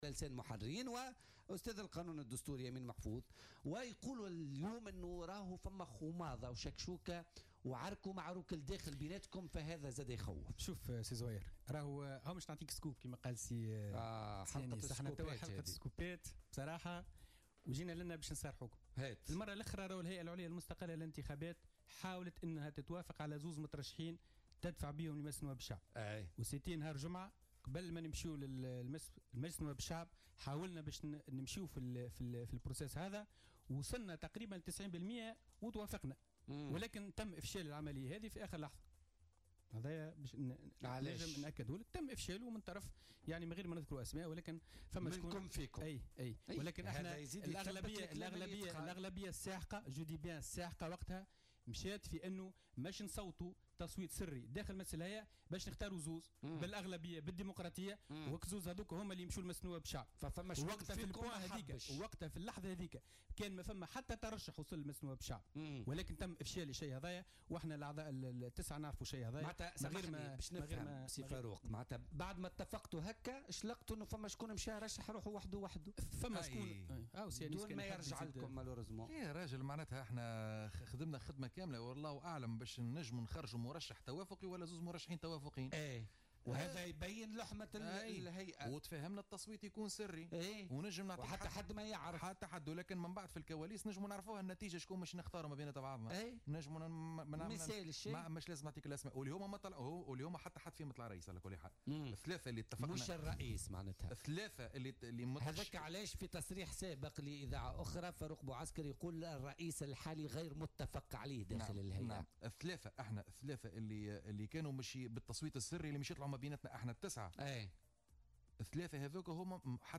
أكد عضو الهيئة العليا المستقلة للانتخابات فاروق بوعسكر ضيف بولتيكا اليوم الإثنين 20 نوفمبر 2017 أن الهيئة حاولت التوافق على مترشحين لرئاستها وتقديمهما لمجلس نواب الشعب والتصويت سريا لهما قبل أن يتم افشال العملية في اخر لحظة من قبل بعض الأعضاء الذين تحفظ عن ذكر أسمائهم.